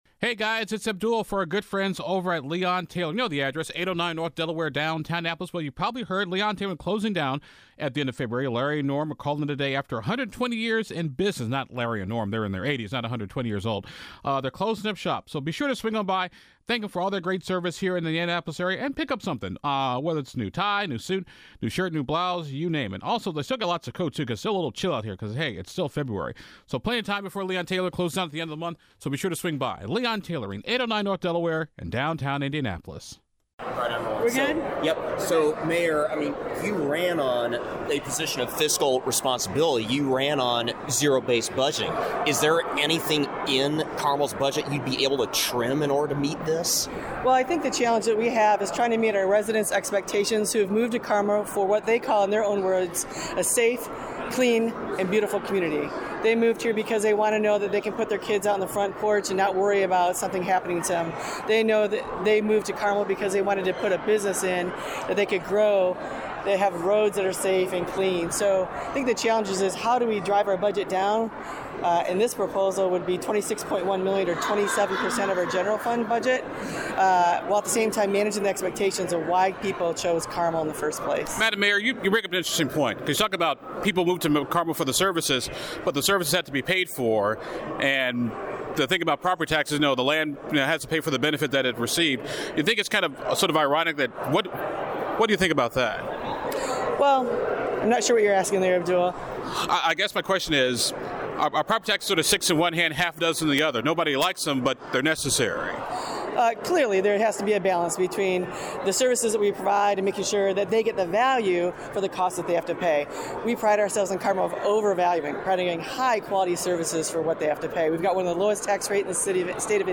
Carmel Mayor Sue Finkham and Terre Haute Mayors Bandon Sakun testified this morning that while they support tax reform, they say police, fire and public safety can be on the line as that is where most of their property tax dollars go.
Carmel-Mayor-Sue-Finkhim.mp3